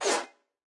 Media:HogRider_baby_atk_1.wavMedia:HogRider_base_atk_1.wav 攻击音效 atk 初级和经典及以上形态攻击音效
HogRider_baby_atk_1.wav